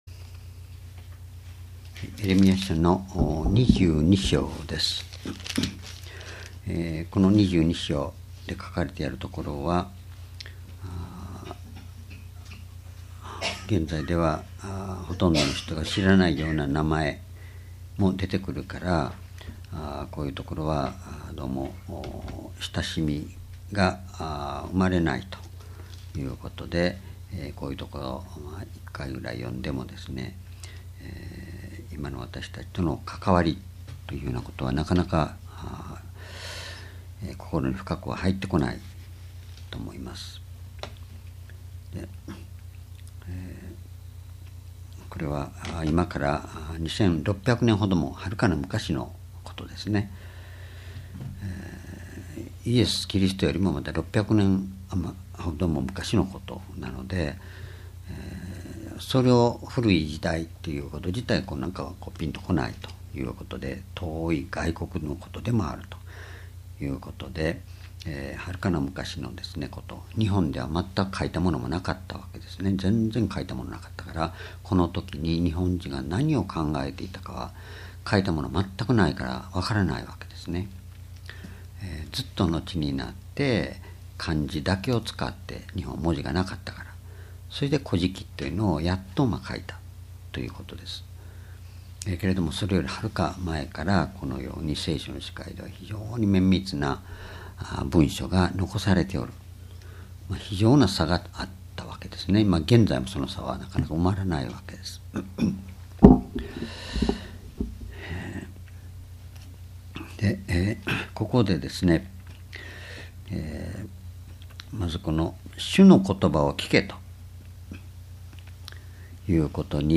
16年11月01日 夕拝｢神を知る道｣エレミヤ書22章､
主日礼拝日時 2016年11月01日 夕拝 聖書講話箇所 エレミヤ書22章 「神を知る道」 ※視聴できない場合は をクリックしてください。